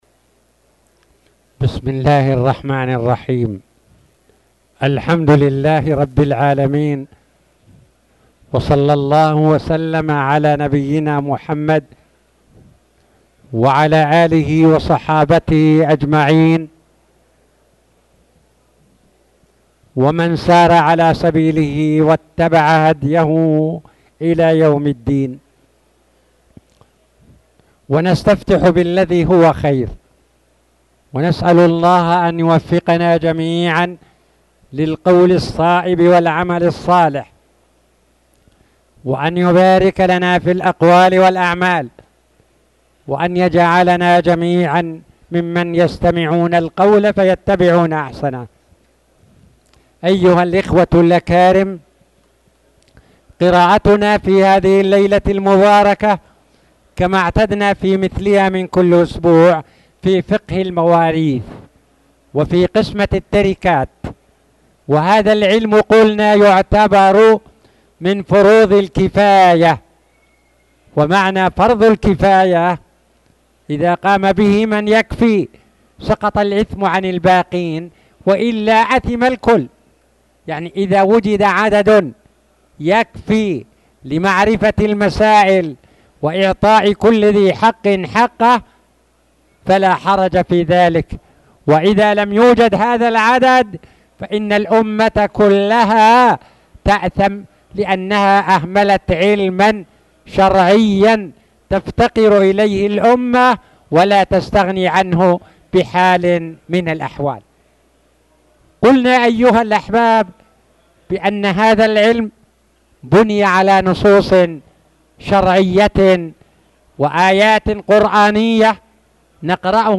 تاريخ النشر ٢٠ ربيع الأول ١٤٣٨ هـ المكان: المسجد الحرام الشيخ